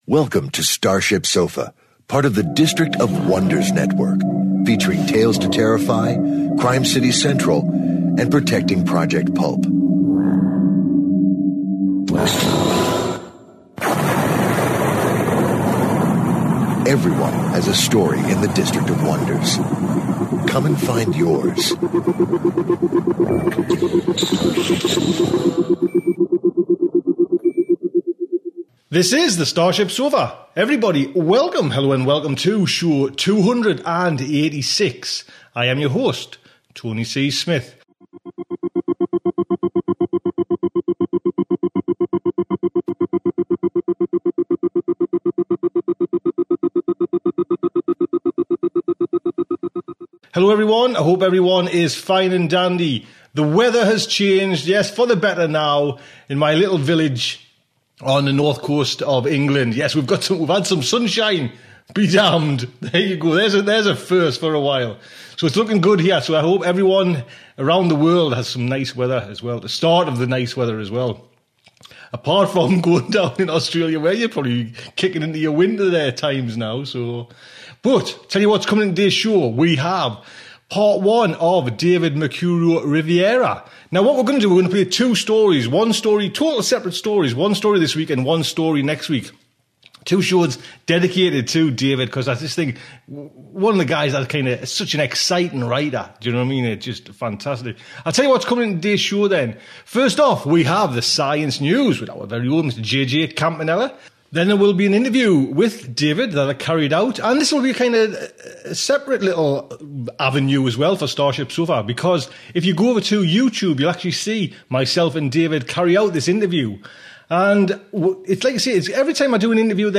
Science News
Interview